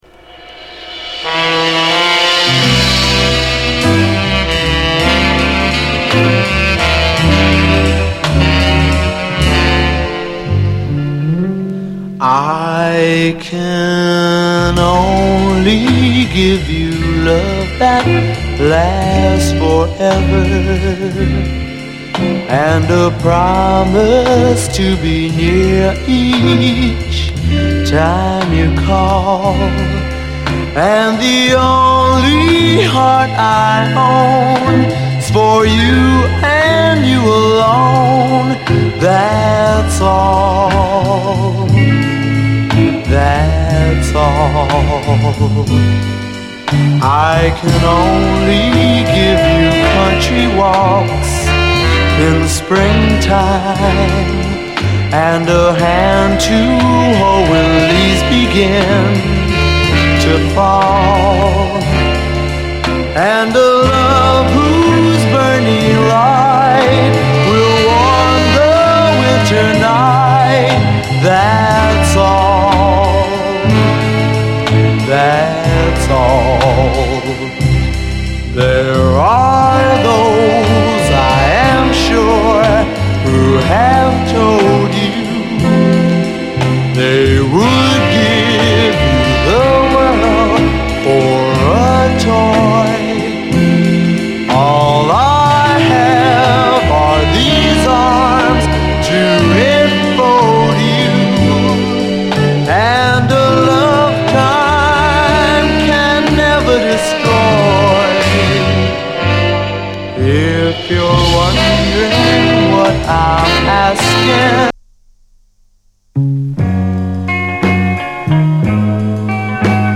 イントロの趣向から中盤〜後半のMOOGソロまで見事な展開力で魅惑の時間を約束する傑作トラックです！
モダン・ブギー
溢れ出る多幸感！